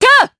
Seria-Vox_Attack3_jp.wav